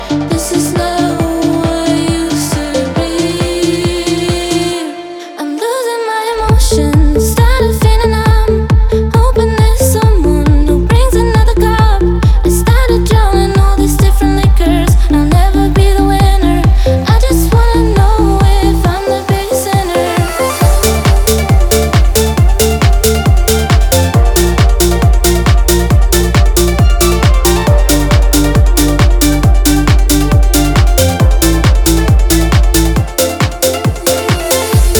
Жанр: Хаус